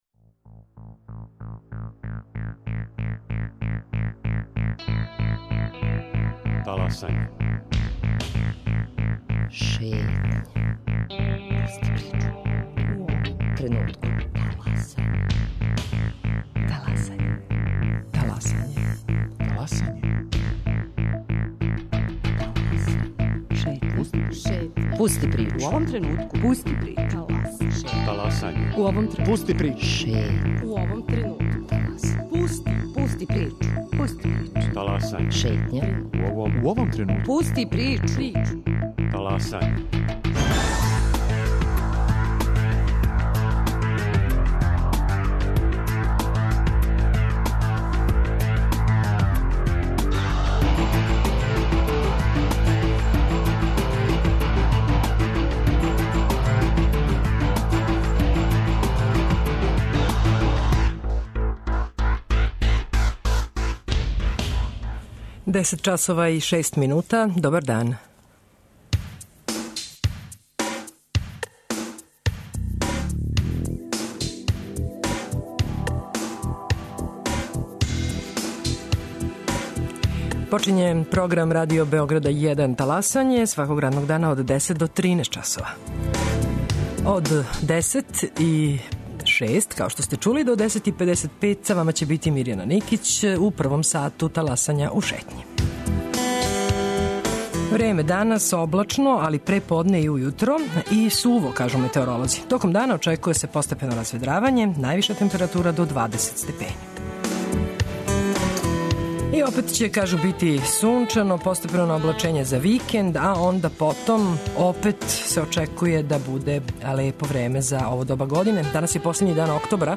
Радио Београд 1, 10.05